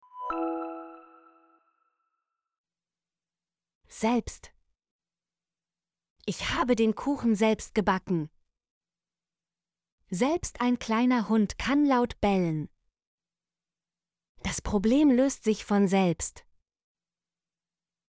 selbst - Example Sentences & Pronunciation, German Frequency List